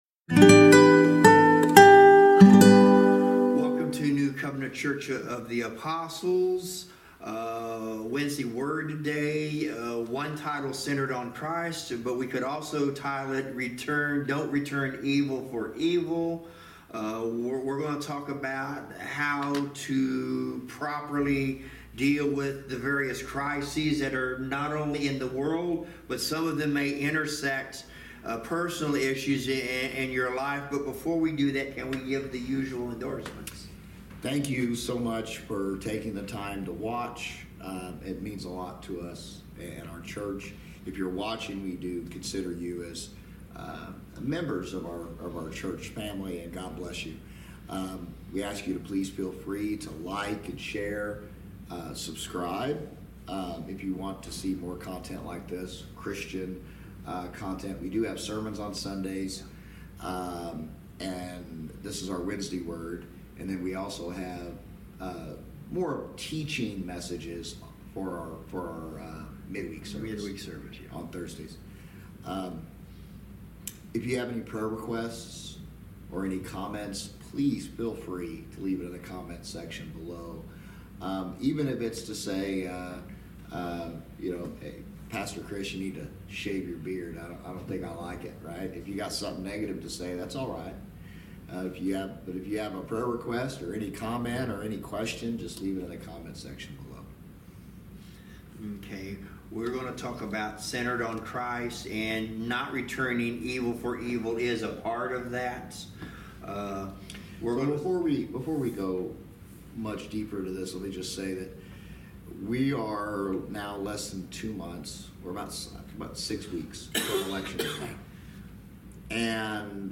Service Type: Wednesday Word Bible Study